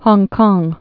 (hŏngkŏng, -kŏng, hôngkông, -kông)